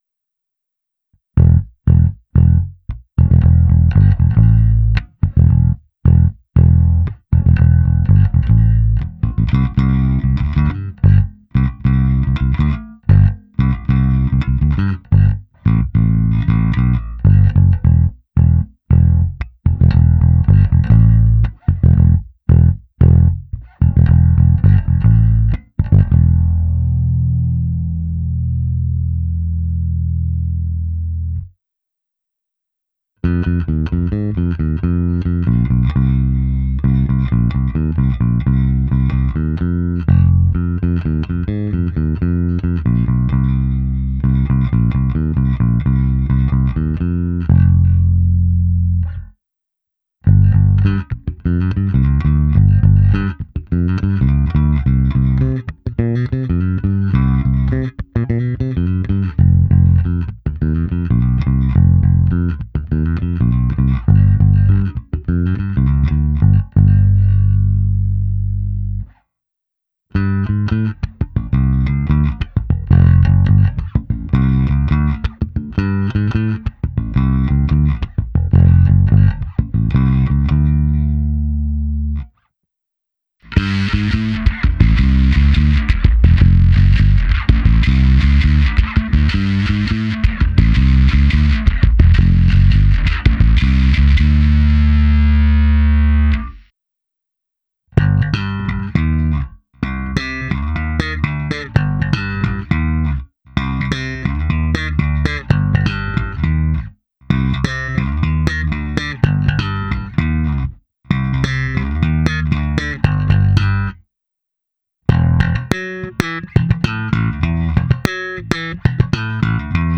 Jestliže zvukový projev lípy je obecně měkčí, temnější, jelikož se jedná o měkké dřevo, snímače tento projev upozadily, zvuk je naprosto klasický průrazně jazzbassový, s pořádnou porcí kousavých středů.
Nahrávka se simulací aparátu na oba snímače, použito i zkreslení a hra slapem